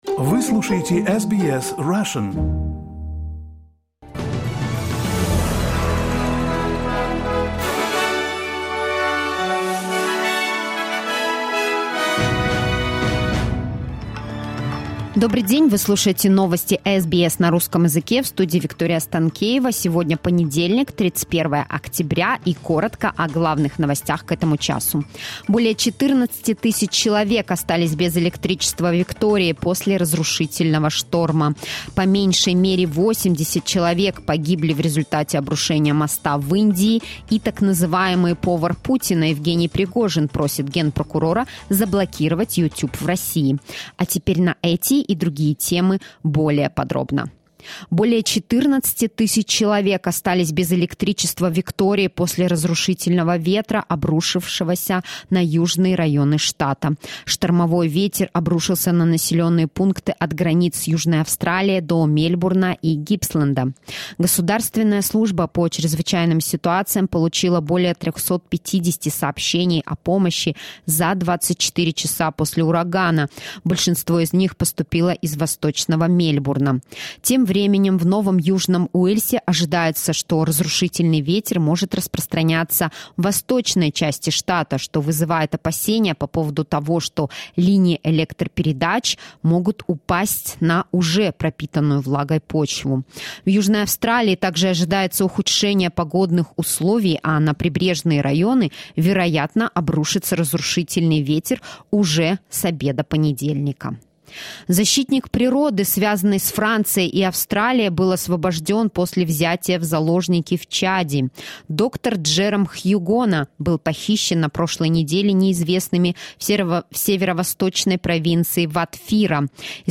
SBS news in Russian - 31.10.2022